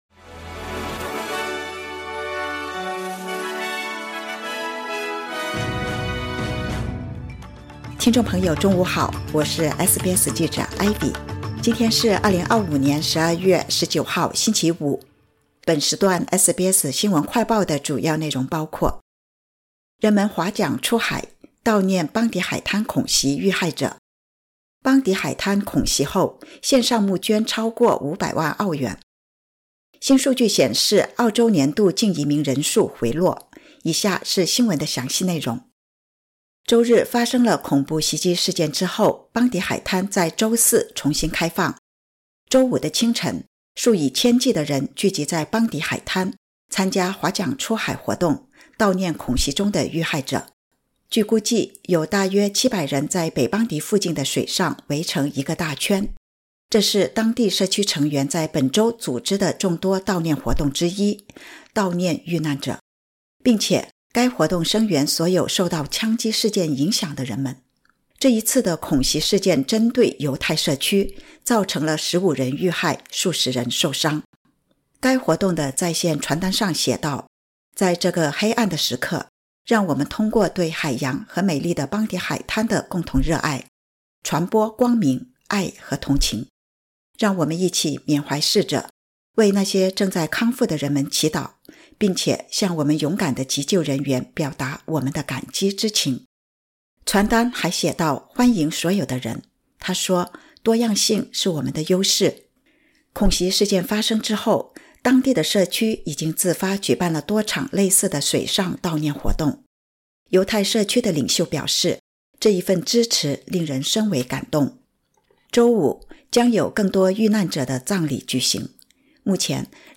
【SBS新闻快报】人们划桨出海 悼念悉尼邦迪海滩恐袭遇害者